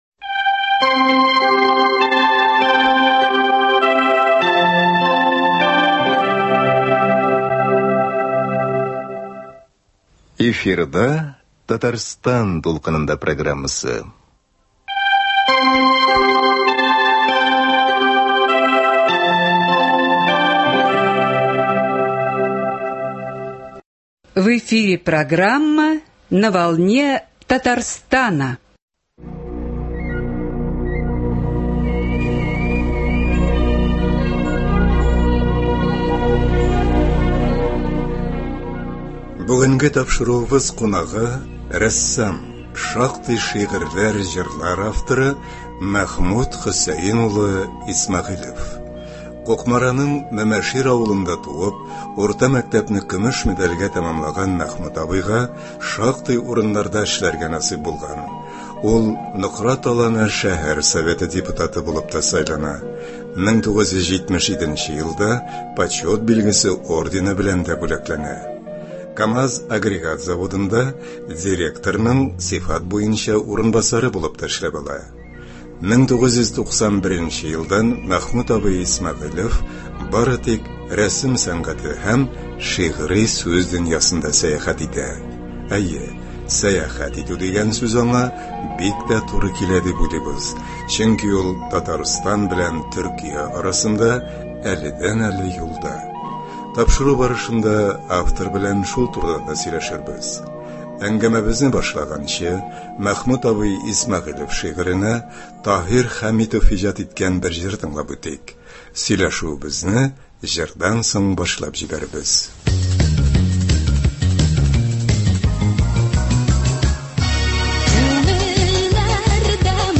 Әңгәмә барышында без аның иҗаты турында кызыклы фикерләр ишетербез.